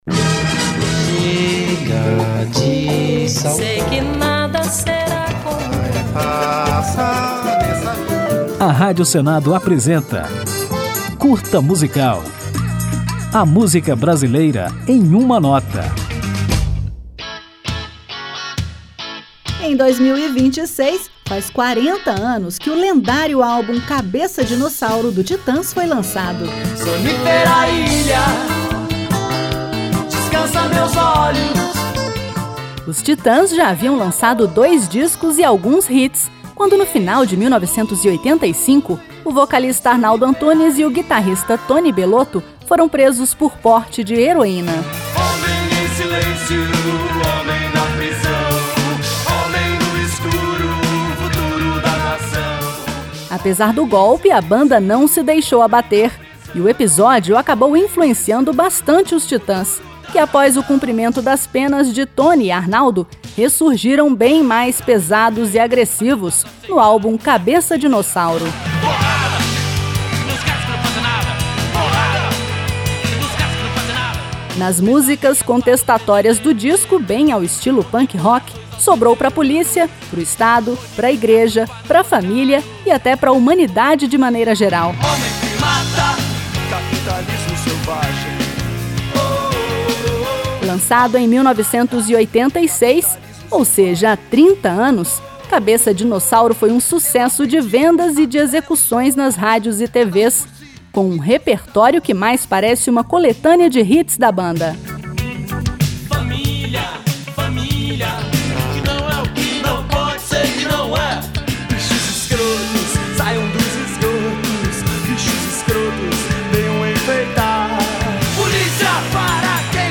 Depois de conferir tudo sobre este álbum clássico da música brasileira, vamos ouvir os Titãs num sucesso do disco Cabeça Dinossauro, a música AA UU.